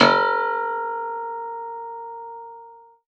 53x-pno03-A2.wav